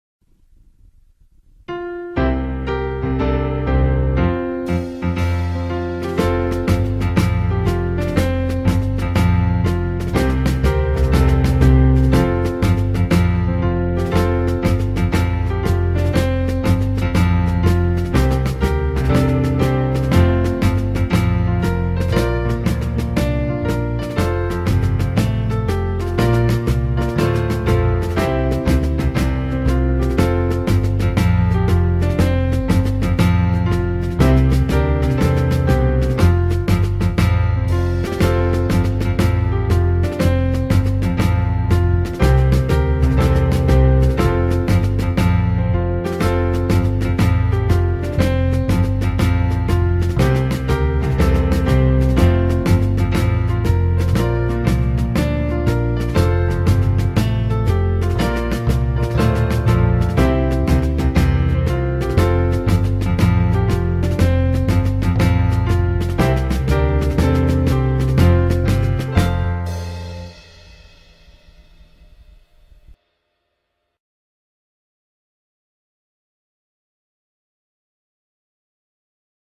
anthem.wav